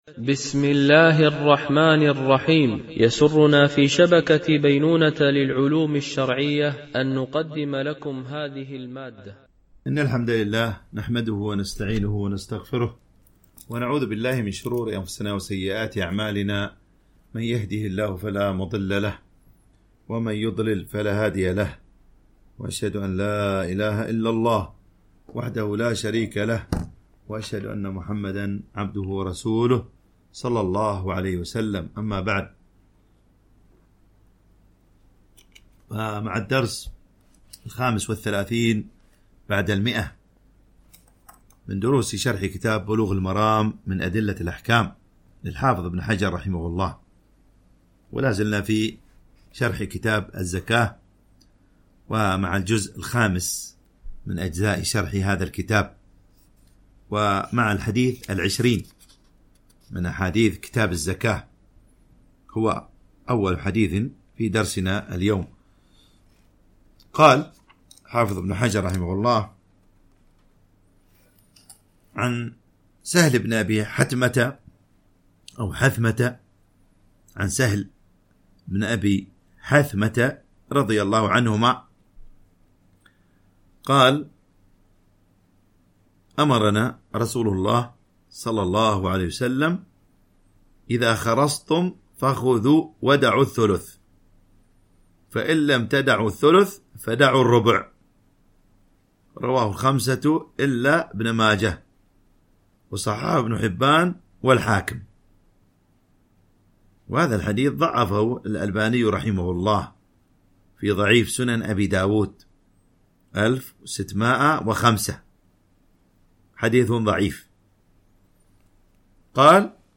شرح بلوغ المرام من أدلة الأحكام - الدرس 135 ( كتاب الزكاة - الجزء الخامس- الحديث 618 - 623 )
MP3 Mono 44kHz 64Kbps (CBR)